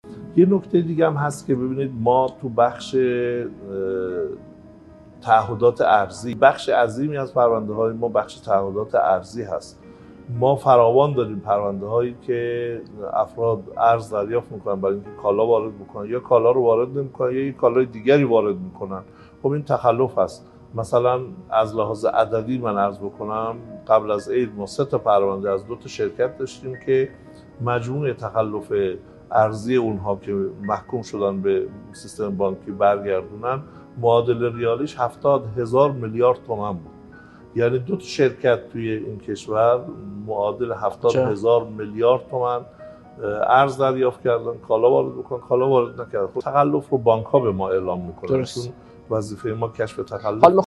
رئیس سازمان تعزیرات: